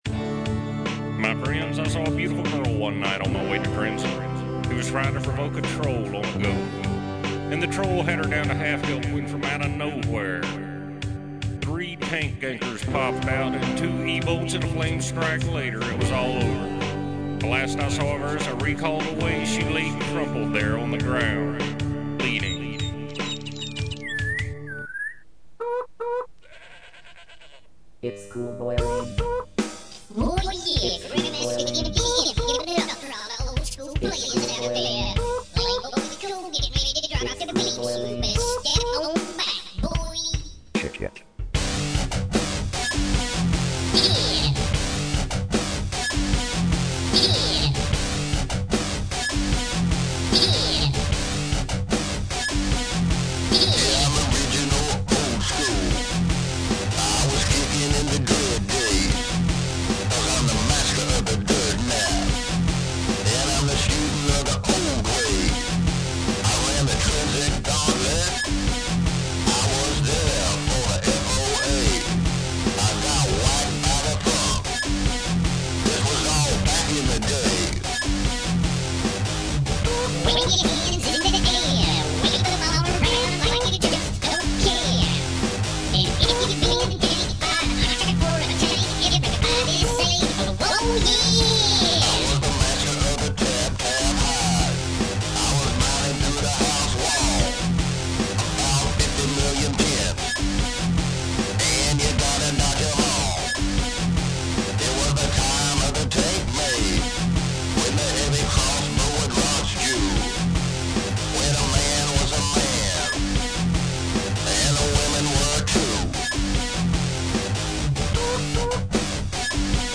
UO was a dead wasteland by this point, and I was feeling melancholic.  But I actually rapped on this one, with plenty of distortion all arround.